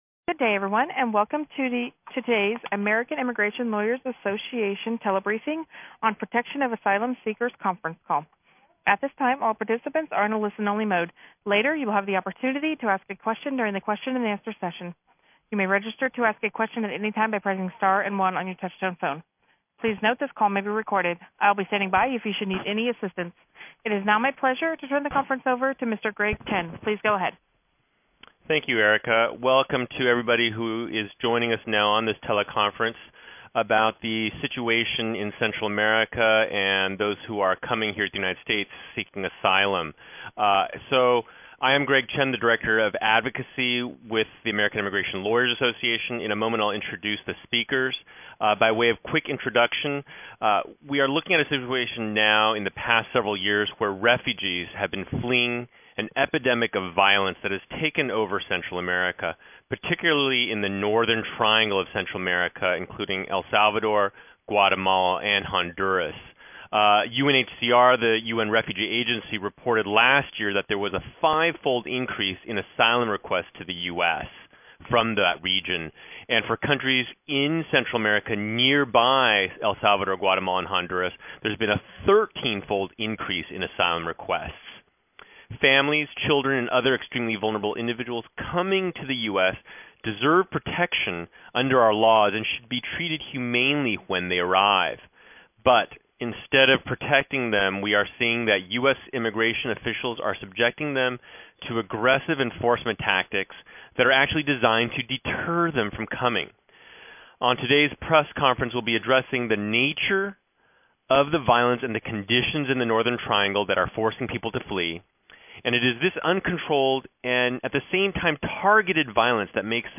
AILA hosted a telebriefing for press and Hill staff to discuss the conditions in Central America forcing families to flee, the ways they have been denied due process, and most importantly, what our nation needs to do to make this right.